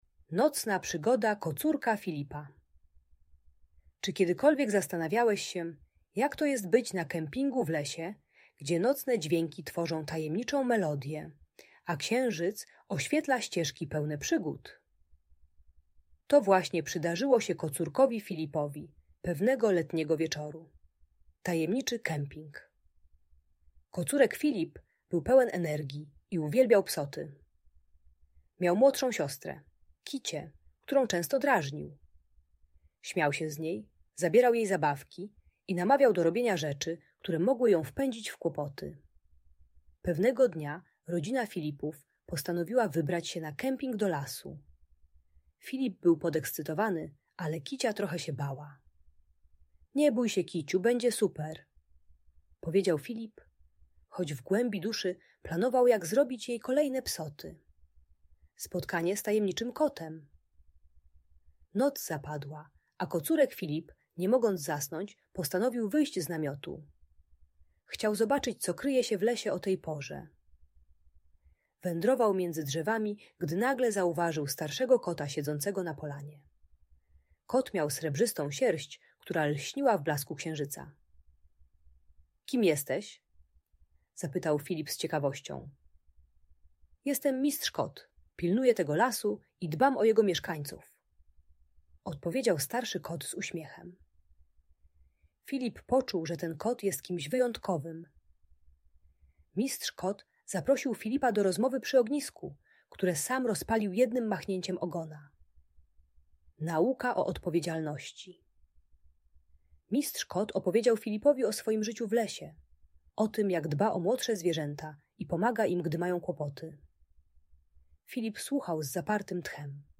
Nocna przygoda Kocurka Filipa - magiczna historia - Audiobajka